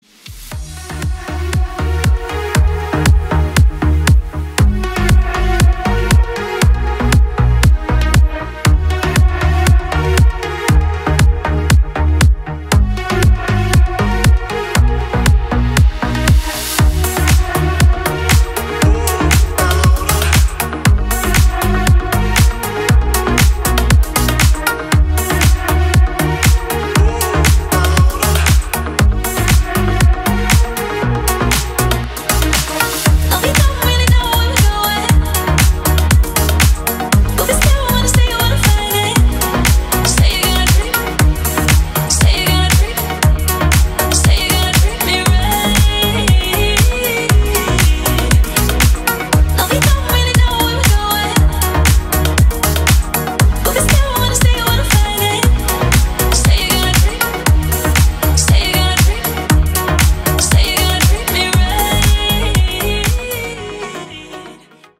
• Качество: 320, Stereo
атмосферные
Electronic
Стиль: deep house.